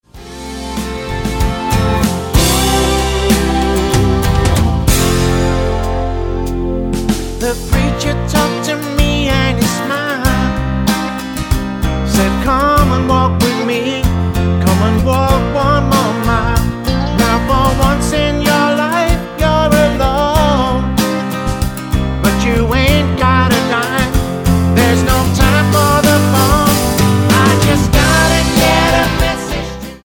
Tonart:C-D Multifile (kein Sofortdownload.
Die besten Playbacks Instrumentals und Karaoke Versionen .